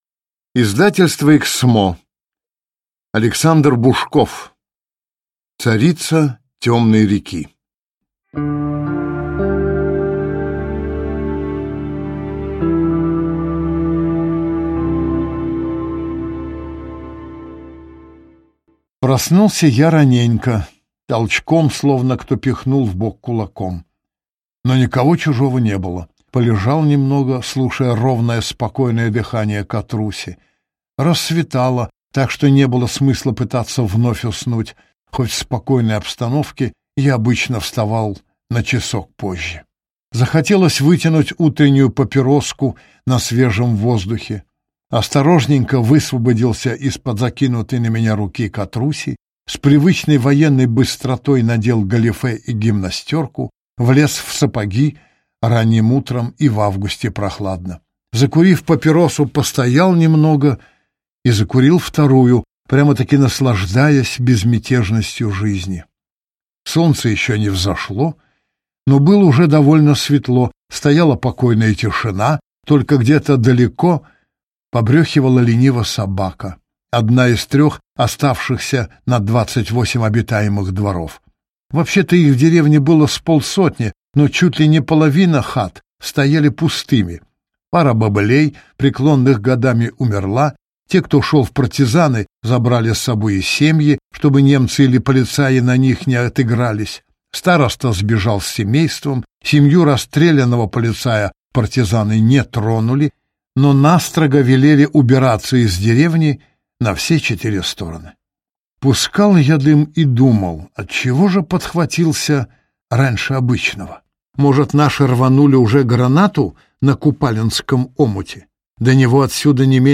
Аудиокнига Царица темной реки | Библиотека аудиокниг